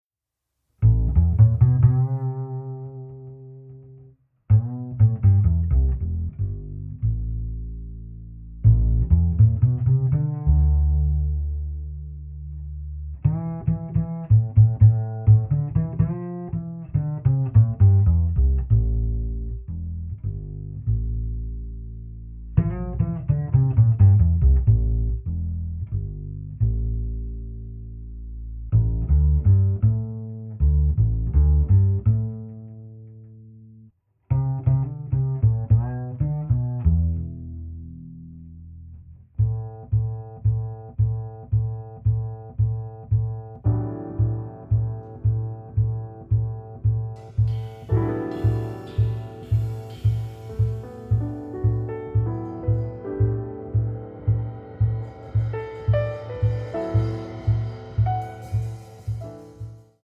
alto sax
piano
bass
drums
guitar